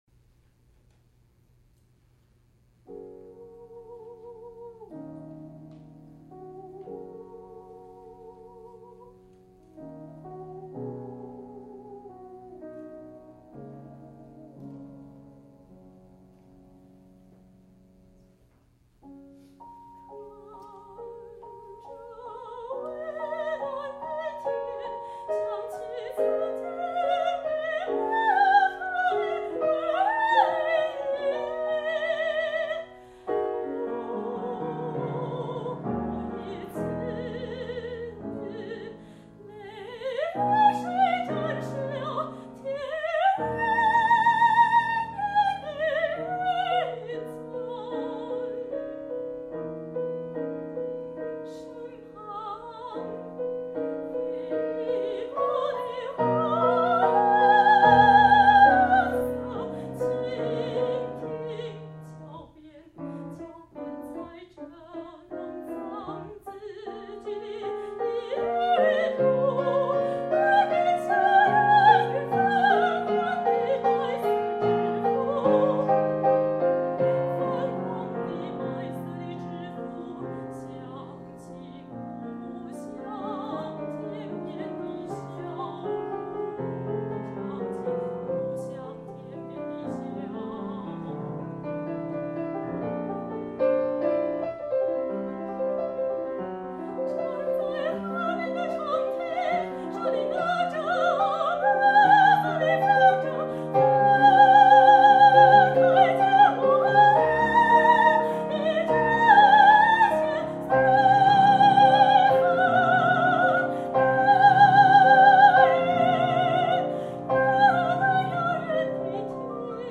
for High Voice and Piano
Bach Recital Hall, Taipei, Taiwan
soprano
piano
This is the world premiere recording.